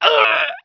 pain2.wav